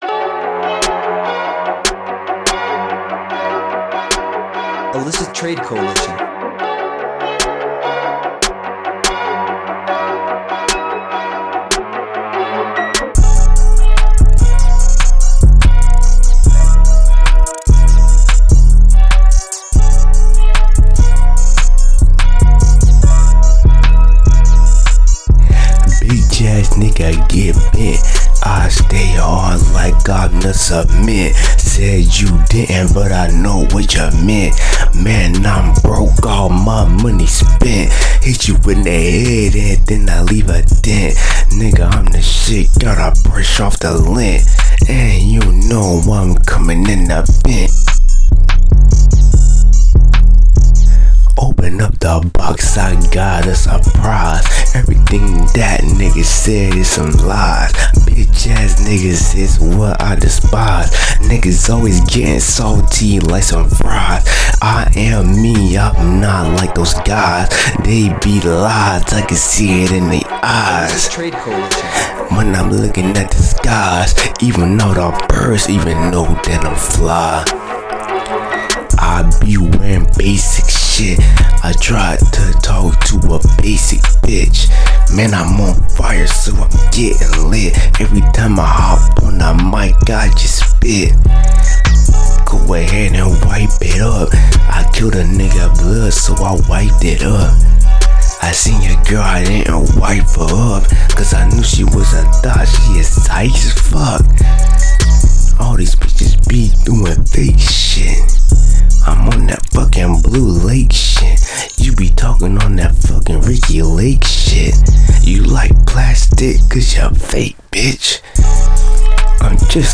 5 freestyles made just for fun.